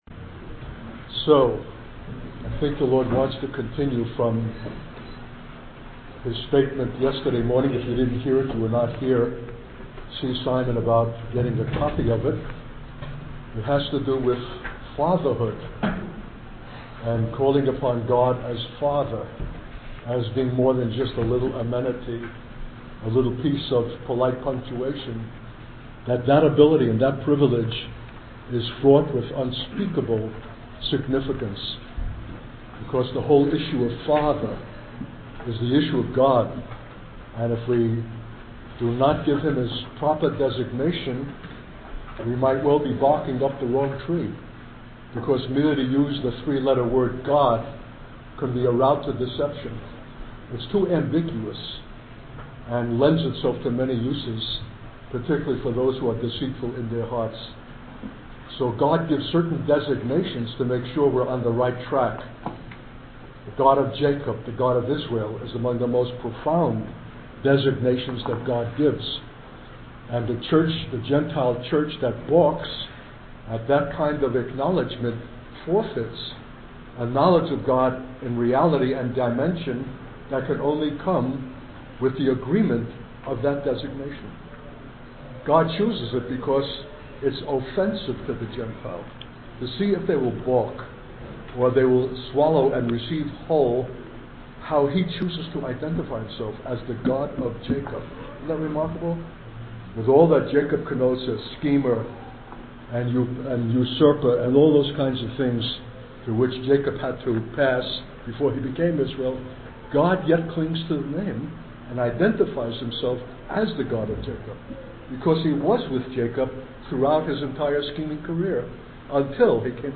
In this sermon, the speaker raises the question of how we can call upon God as our Father. He emphasizes the importance of understanding the true meaning of sonship and honoring our earthly fathers.